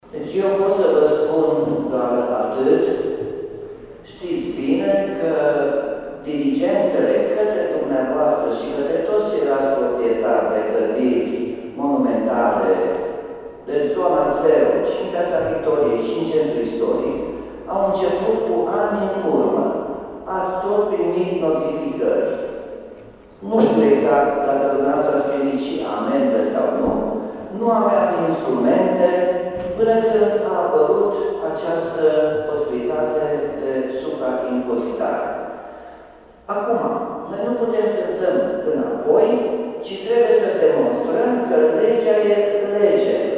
Primarul Nicolae Robu a explicat că primăria nu poate da înapoi niciun milimetru în ceea ce privește suprataxarea celor care nu și-au îngrijit clădirile istorice.